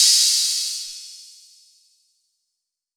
6CRASH 1.wav